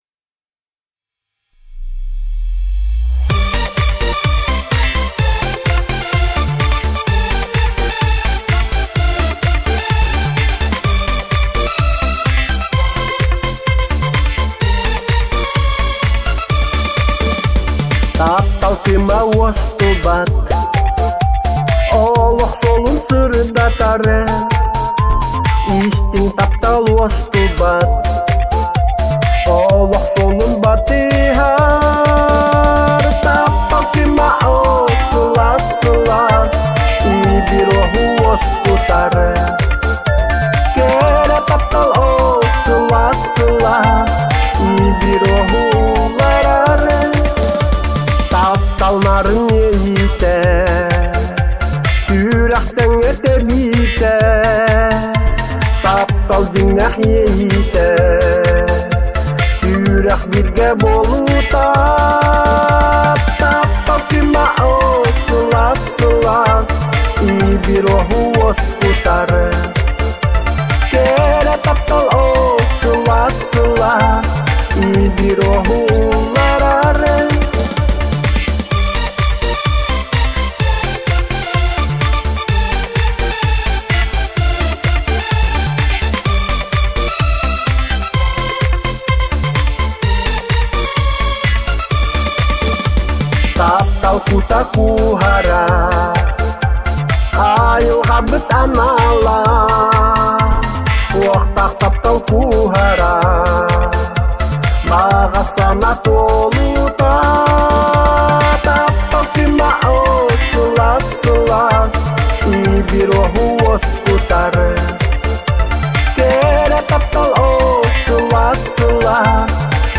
Ыллыыр автор